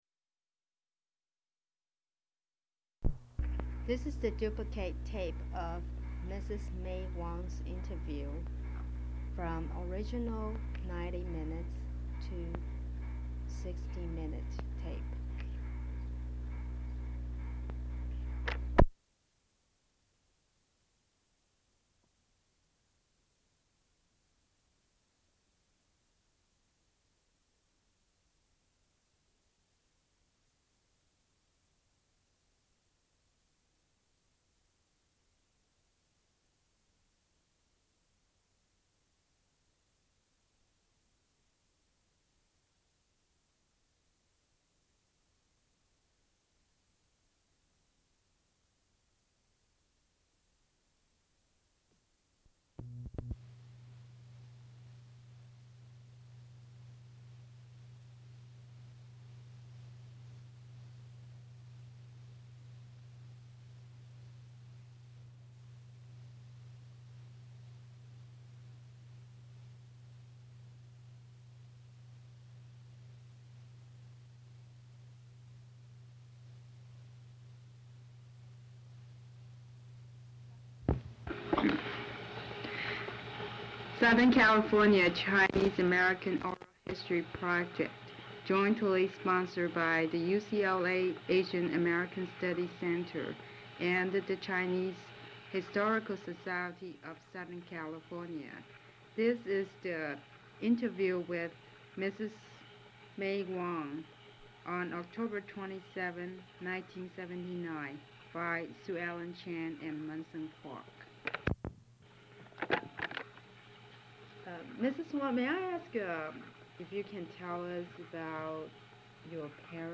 Tape 1, Side A